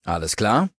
Datei:Maleadult01default dialogueci citknightdirect 00026c4b.ogg